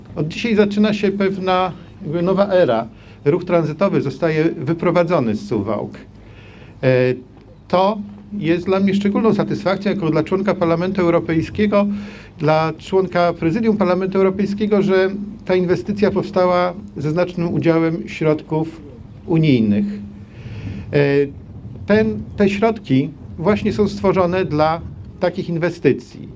O nowej erze w życiu suwalczan mówił też profesor Karol Karski, eurodeputowany i członek prezydium Parlamentu Europejskiego.